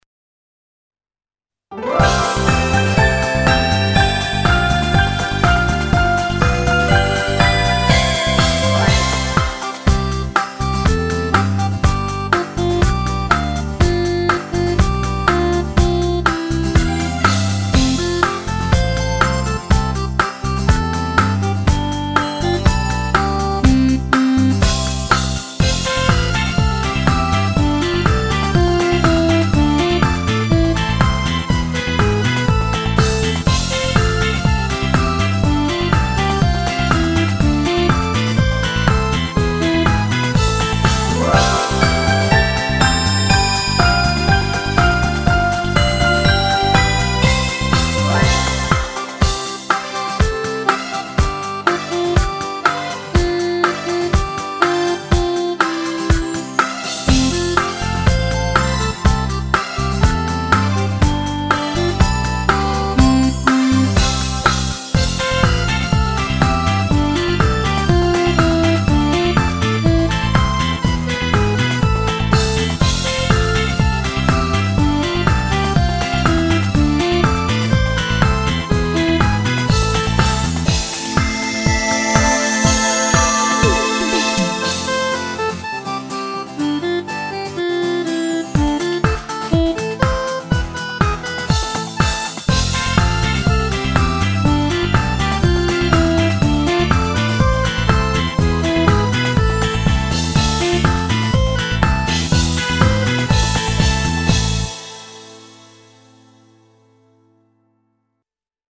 Дитячі